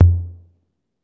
Index of /90_sSampleCDs/Roland - Rhythm Section/DRM_Drum Machine/KIT_CR-78 Kit
TOM 808 TO0E.wav